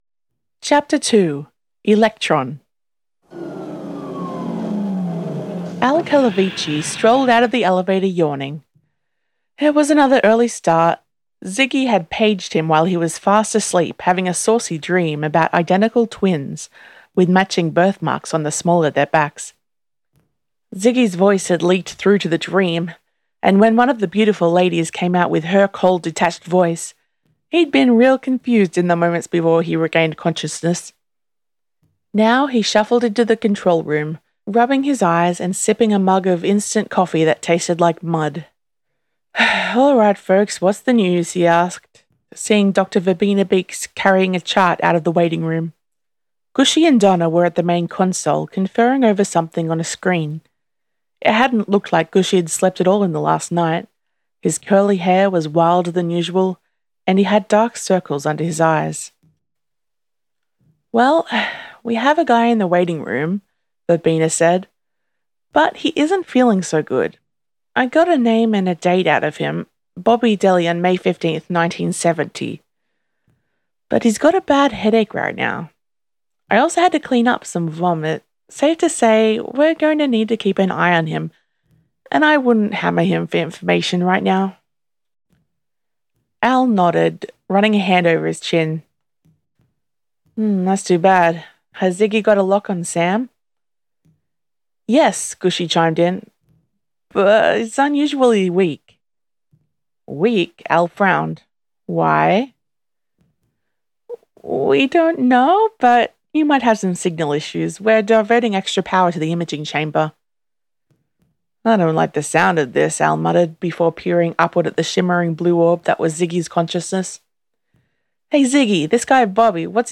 Listen to/download this chapter narrated by the author: